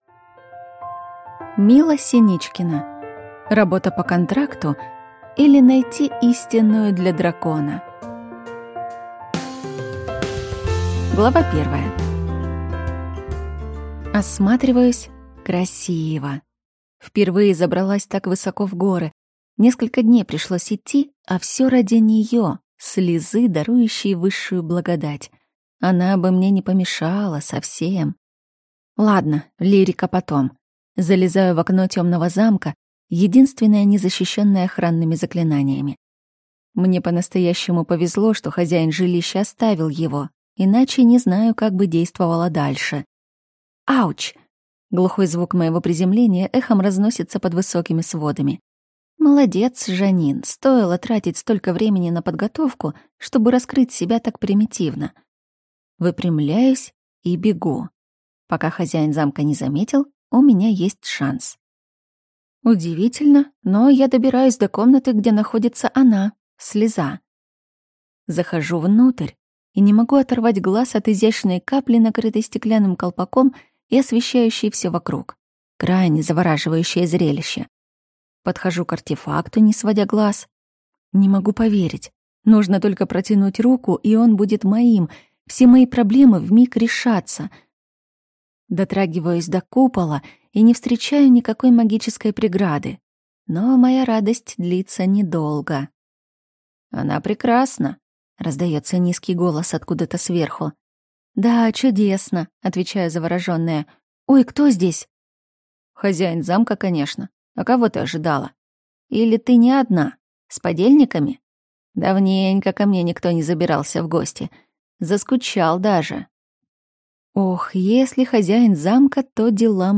Аудиокнига Работа по контракту, или Найти истинную для Дракона | Библиотека аудиокниг